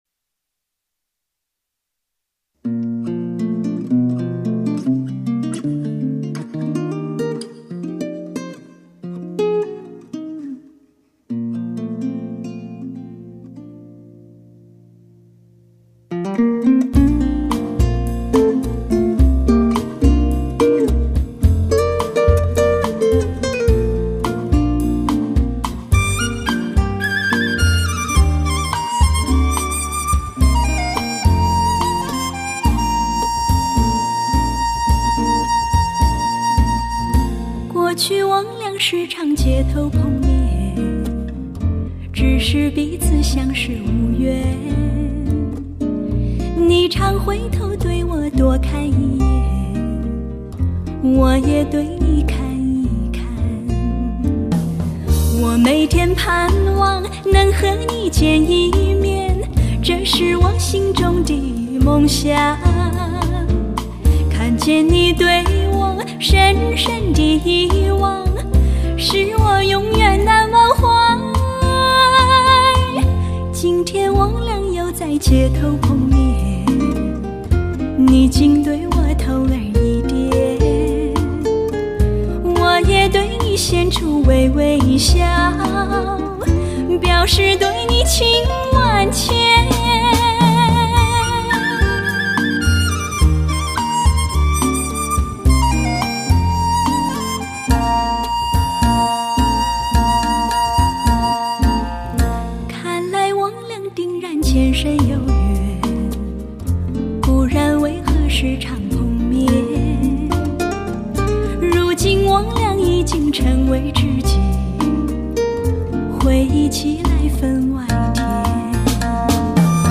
高相似度的声音，超高水平的录音
CD采用HD-AUDIO高清录音标准精细录音，保证人声饱满温和，乐器清脆干净，整体声场宽阔，线条清爽。